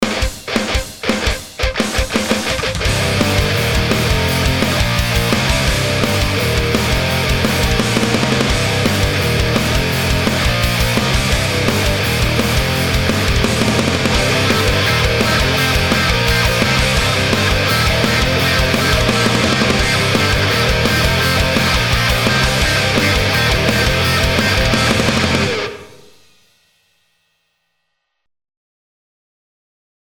- guitar.demo - ����� ����������